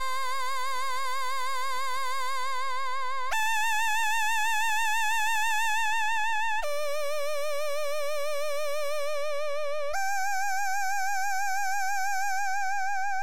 Tag: 145 bpm Trap Loops Organ Loops 2.23 MB wav Key : Unknown